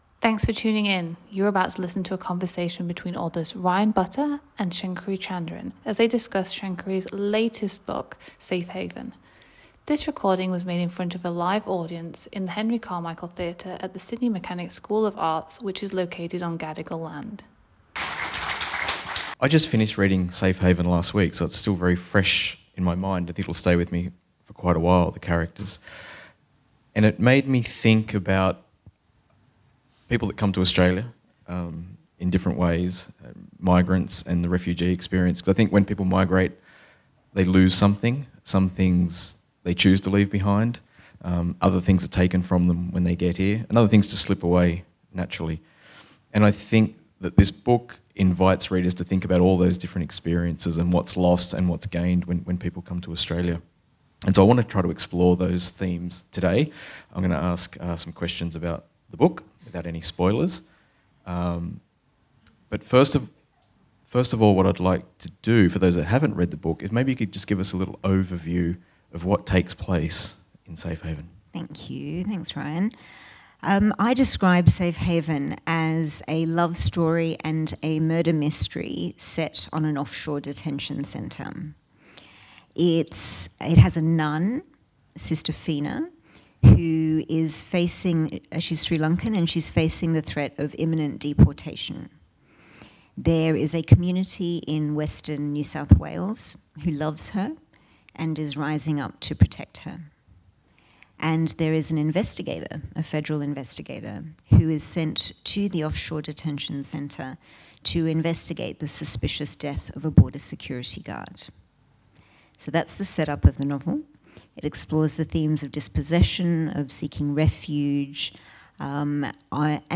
Event Recording: Shankari Chandran – Safe Haven